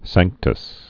(săngktəs)